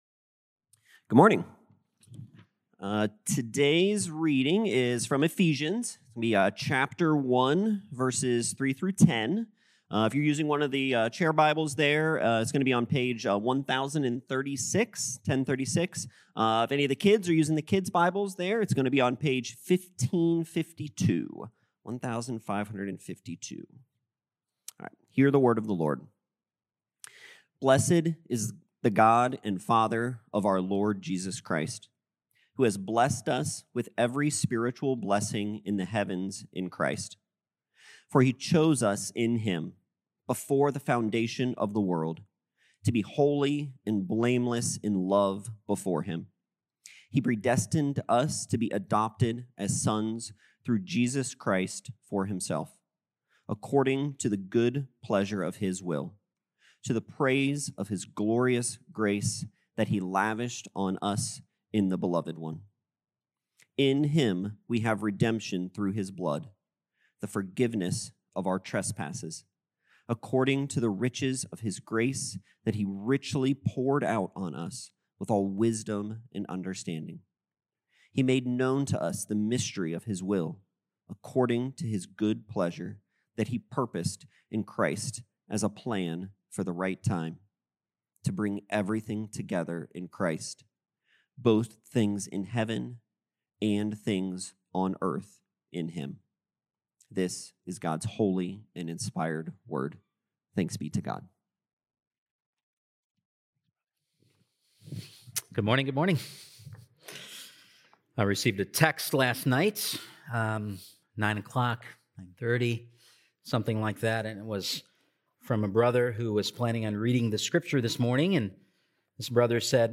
Jan 16th Sermon